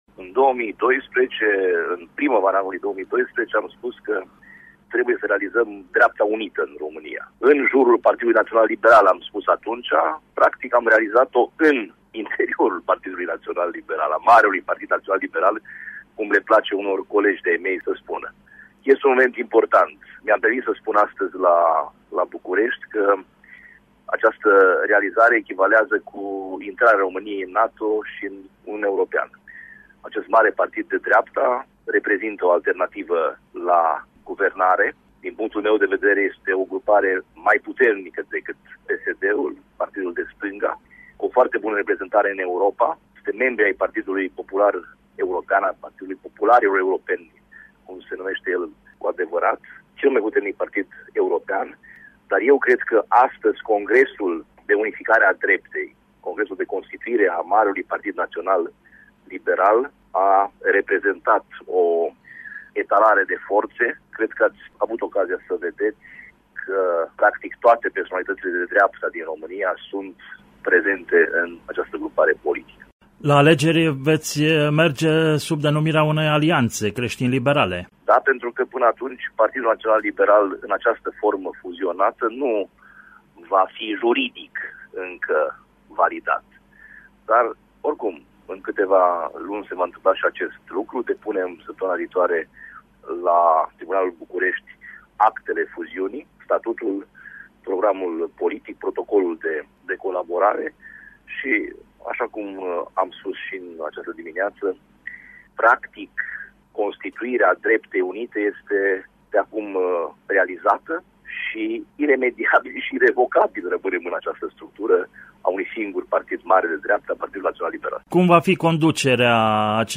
Ascultaţi declaraţia completă a lui Sorin Frunzăverde pentru Radio România Reşiţa: